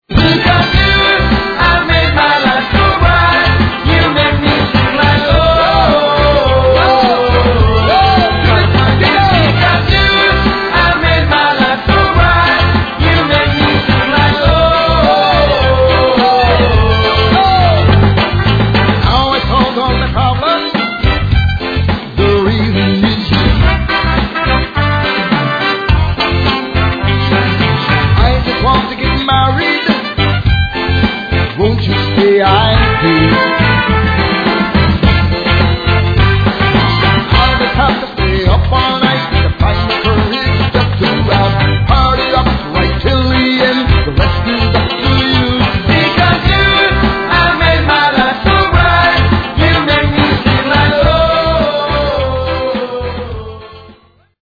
Der Reggae-Hit